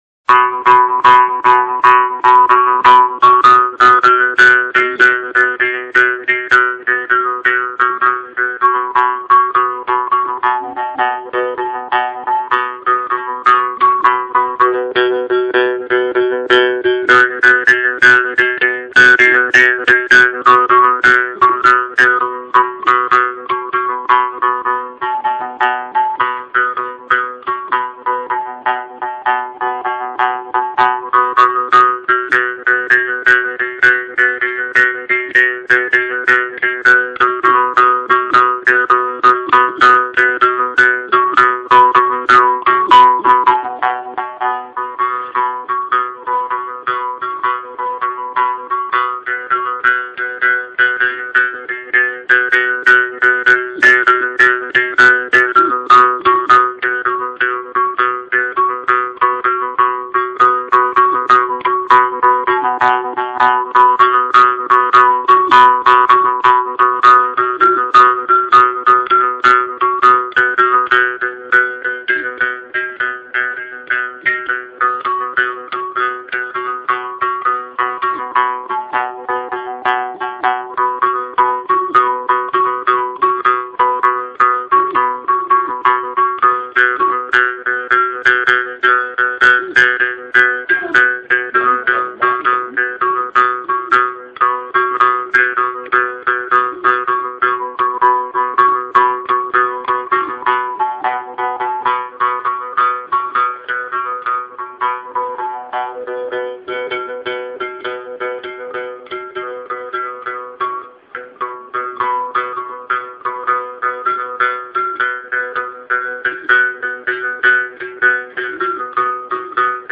Musik Tradisional | Kadazan
Muzik tradtisional etnik Kadazan-Bungkau Muzik tradtisional etnik Kadazan Muzik Kebudayaan Sabah Auto advance Muzik tradtisional etnik Kadazan-Bungkau | Muzik tradtisional etnik Kadazan | Muzik Kebudayaan Sabah